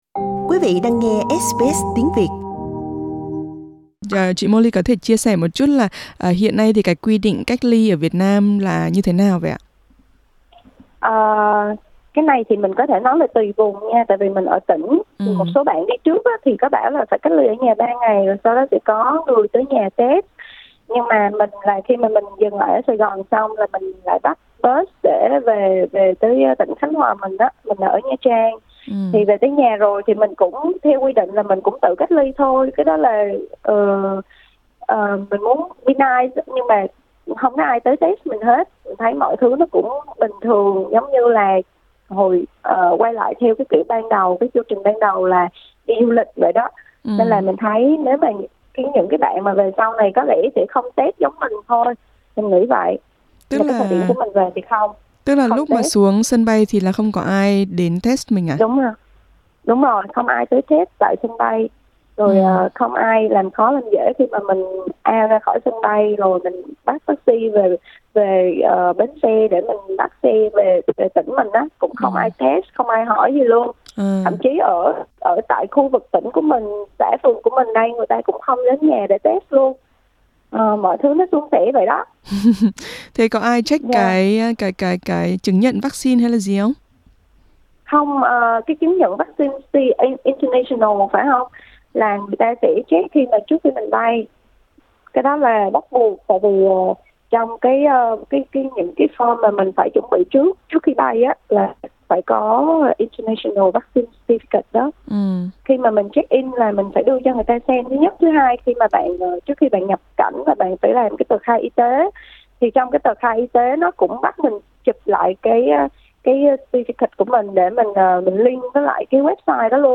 Xin mời quý vị bấm vào hình để nghe toàn bộ nội dung cuộc trò chuyện.